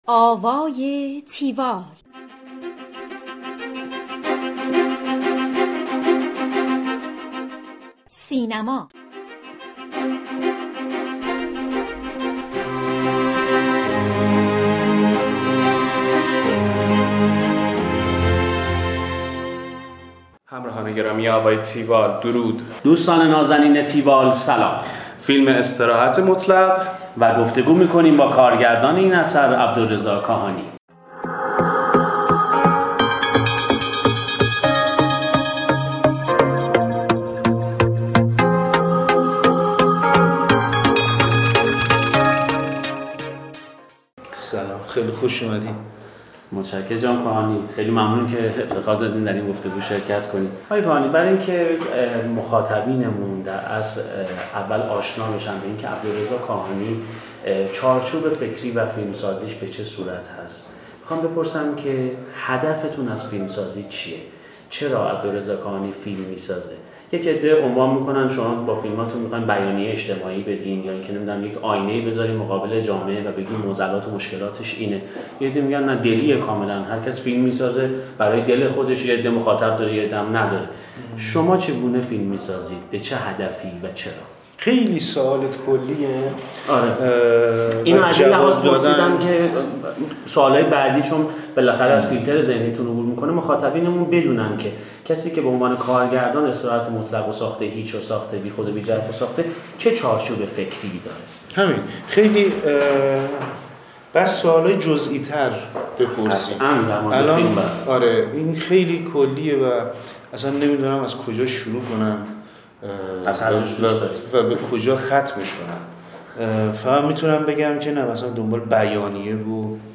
گفتگوی تیوال با عبدالرضا کاهانی (بخش نخست)
tiwall-interview-abdolrezakahani.mp3